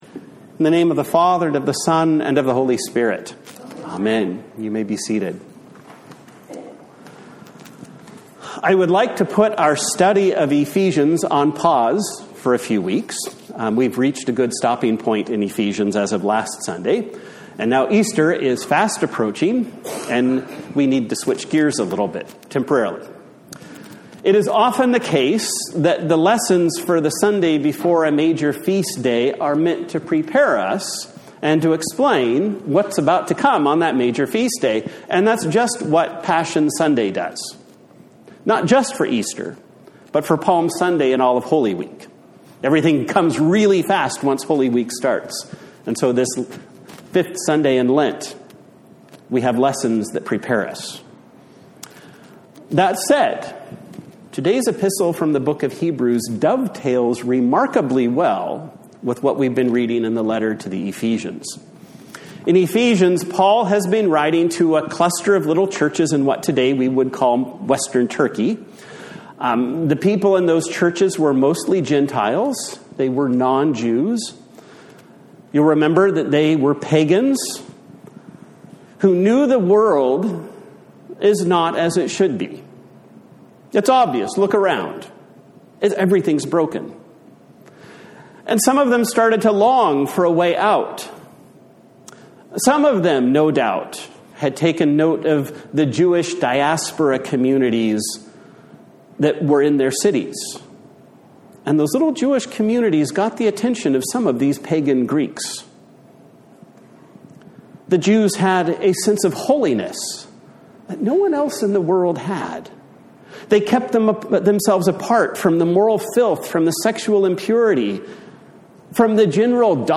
A Sermon for Passion Sunday
Service Type: Sunday Morning